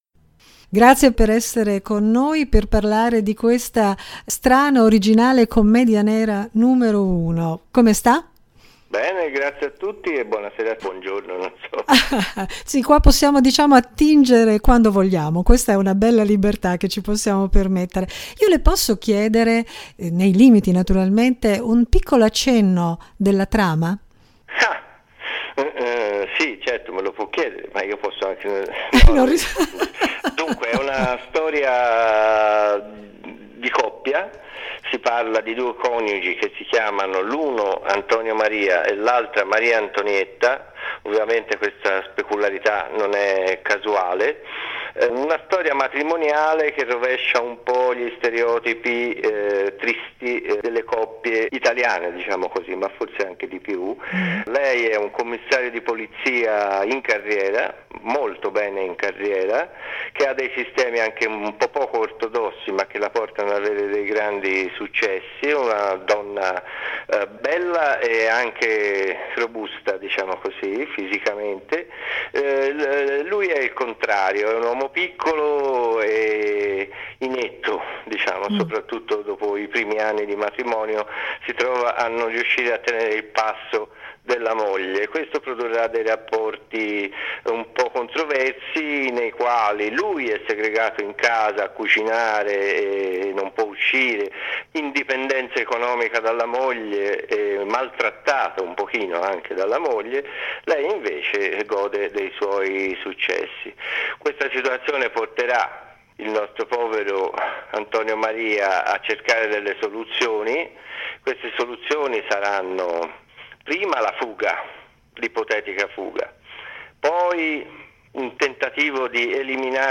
“Commedia nera n°1”: chiacchierata con Francesco Recami